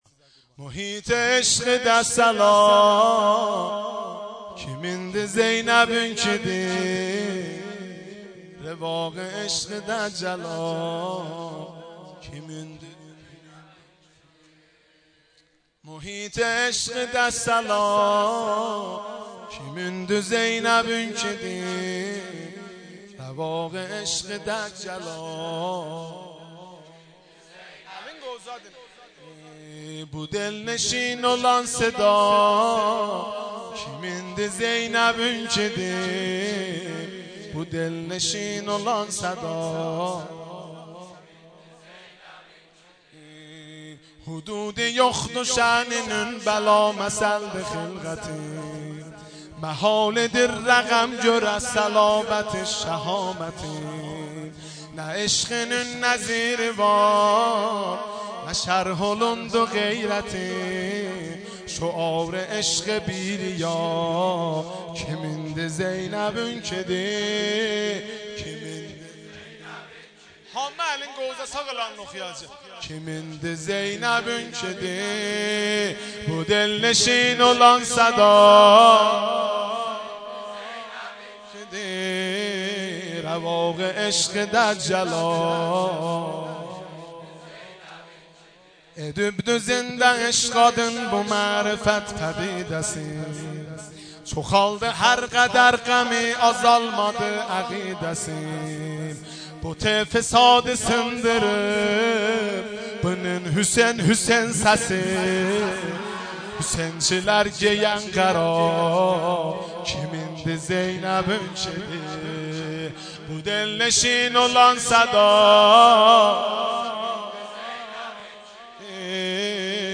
مولودی ترکی میلاد حضرت زینب کبری سلام الله علیها